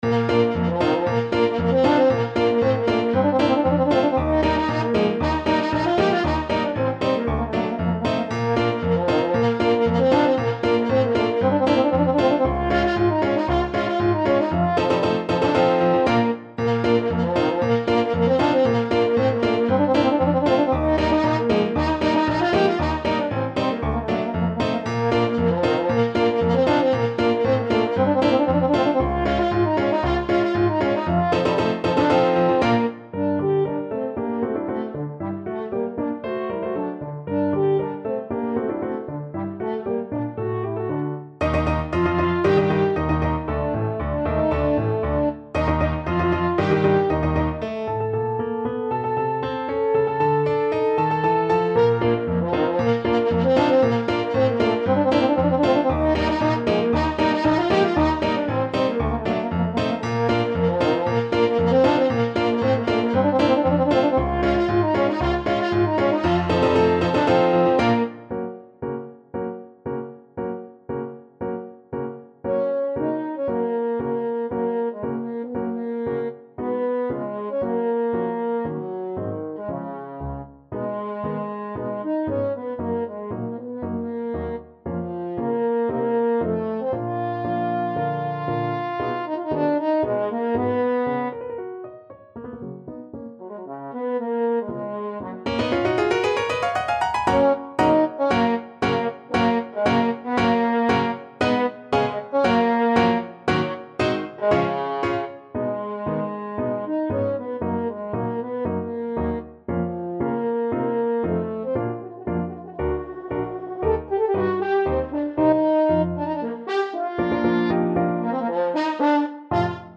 French Horn
Bb major (Sounding Pitch) F major (French Horn in F) (View more Bb major Music for French Horn )
Allegro giocoso =116 (View more music marked Allegro giocoso)
2/4 (View more 2/4 Music)
Classical (View more Classical French Horn Music)